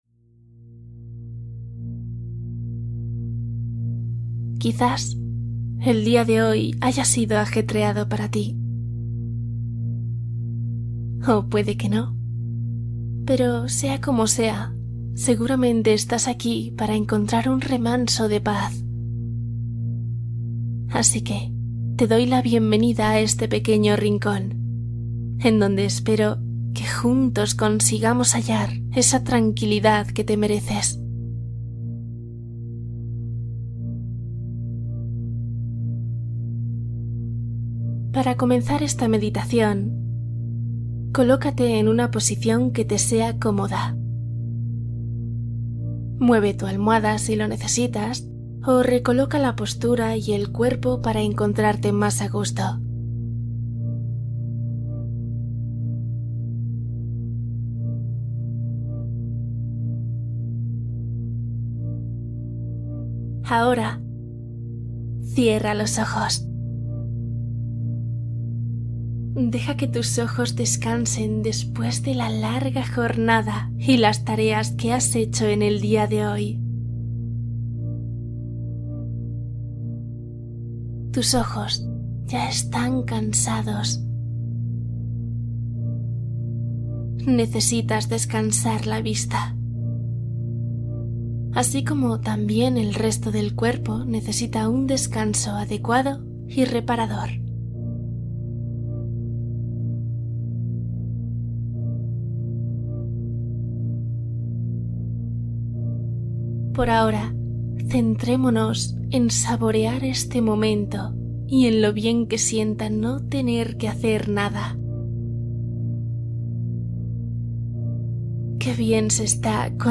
Meditación profunda para dormir | Relajación mente y cuerpo